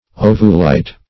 ovulite - definition of ovulite - synonyms, pronunciation, spelling from Free Dictionary Search Result for " ovulite" : The Collaborative International Dictionary of English v.0.48: Ovulite \O"vu*lite\, n. [Ovum + -lite.]